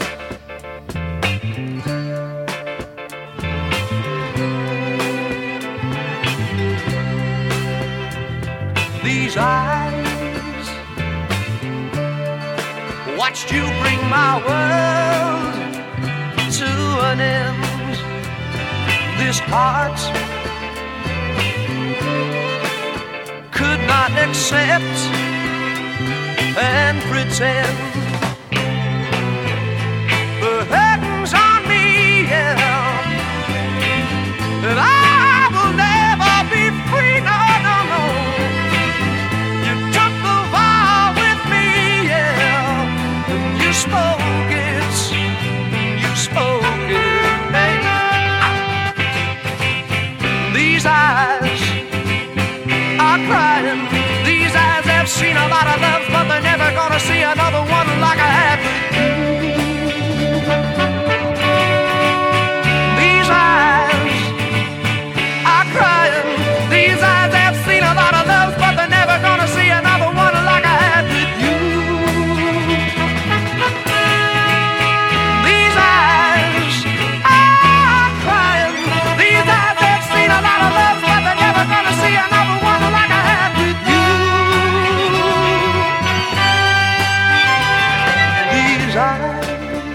ROCK / 60'S
シルヴァートーンズを母体とするカナダ産ロック・バンド！
タイプの轟音ナンバー！